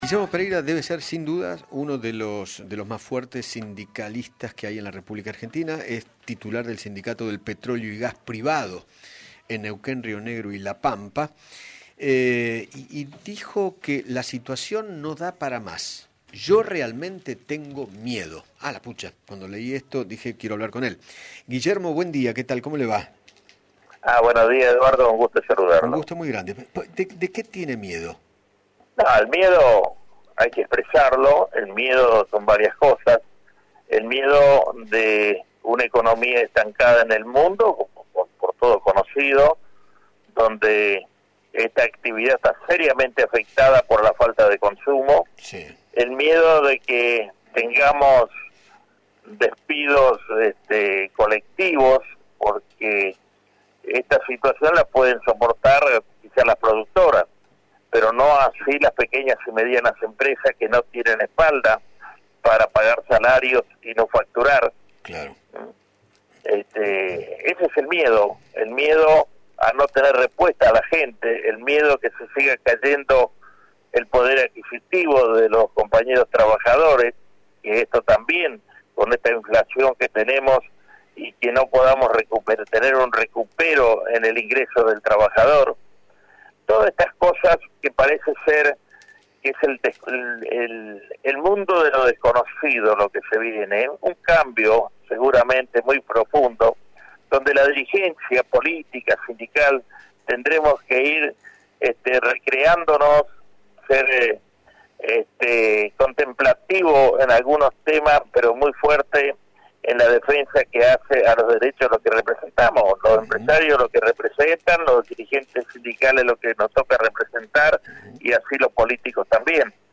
Guillermo Pereyra, titular del Sindicato de Petróleo y Gas Privado de Neuquén, Río Negro y La Pampa dialogó con Eduardo Feinmann sobre la crisis generada por la pandemia del Covid-19 en nuestro país y, además, expresó su preocupación por “no encontrarle, aunque sea medianamente, una solución al Coronavirus”.